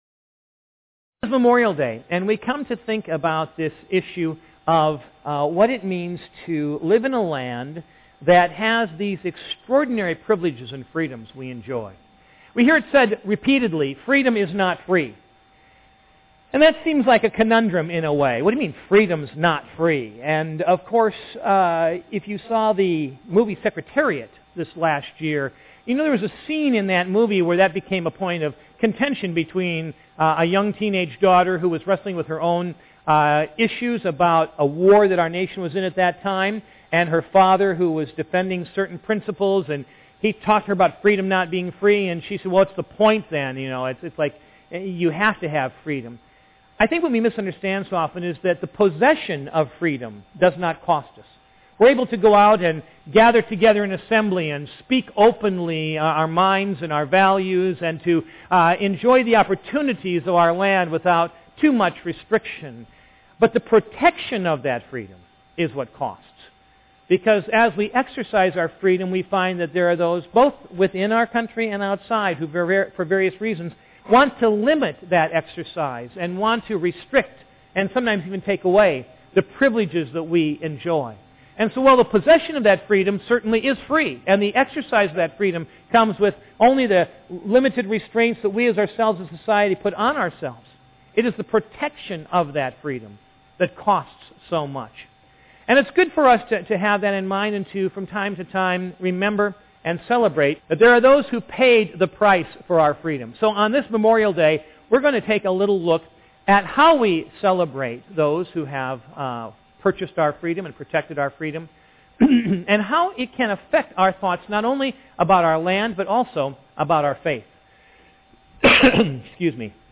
Sunday Morning Message
Memorial Day Message